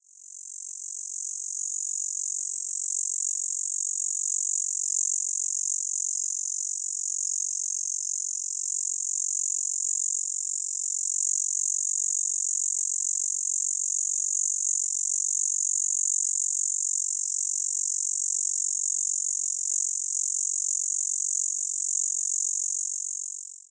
Song type: Call